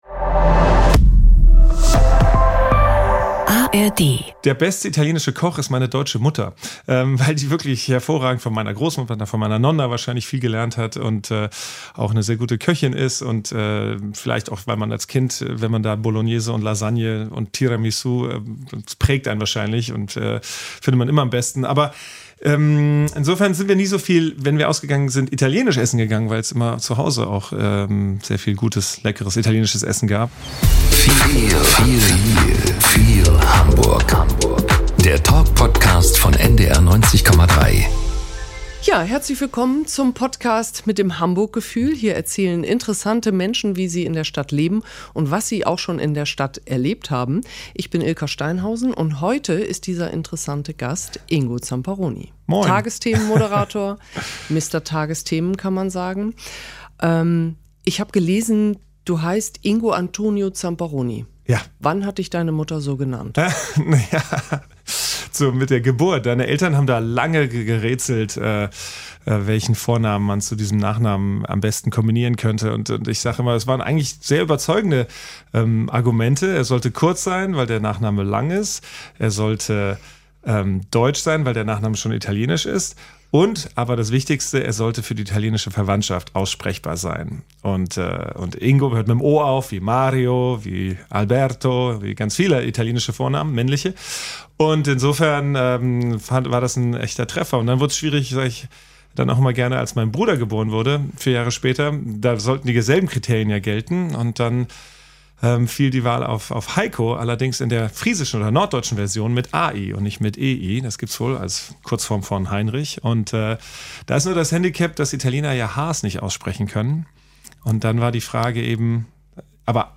Nachrichten - 02.06.2024